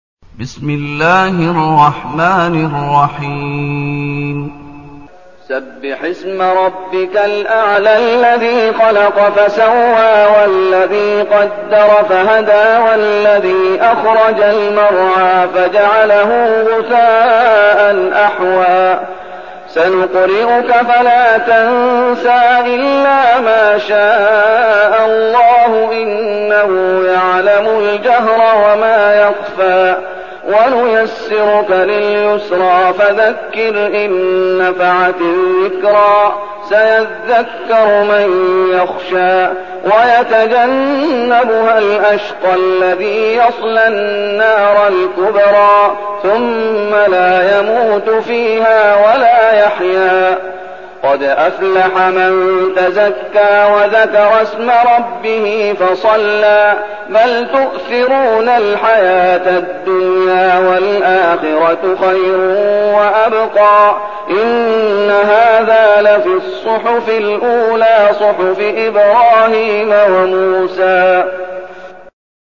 المكان: المسجد النبوي الشيخ: فضيلة الشيخ محمد أيوب فضيلة الشيخ محمد أيوب الأعلى